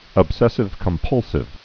(əb-sĕsĭv-kəm-pŭlsĭv, ŏb-)